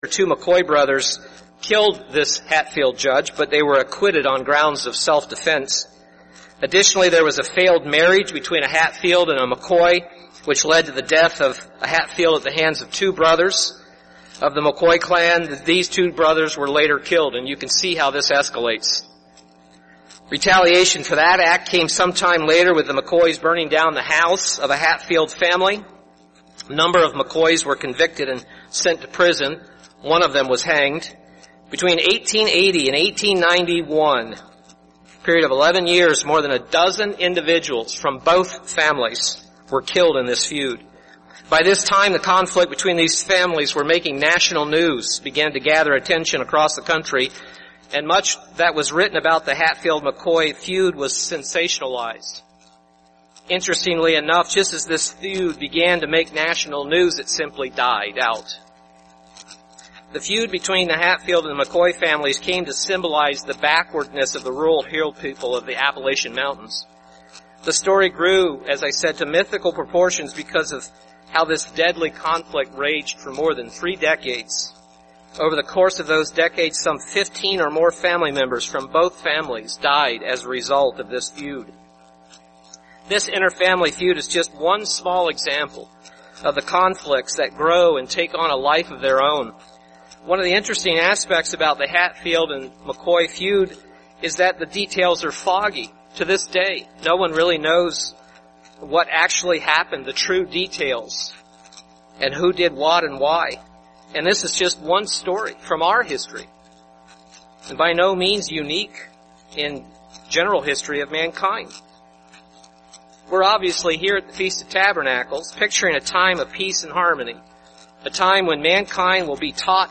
This sermon was given at the Wisconsin Dells, Wisconsin 2013 Feast site.